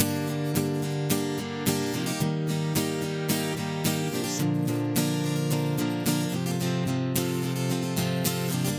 降B大调开放性驾驶节拍
描述：这是一个开放性的原声节奏片段。这段旋律的调性是降B。是一个很好的桥段或合唱。
Tag: 109 bpm Acoustic Loops Guitar Acoustic Loops 1.48 MB wav Key : A